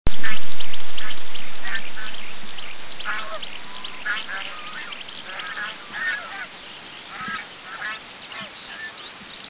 Bird sound recordings made on this trip;
Swan Goose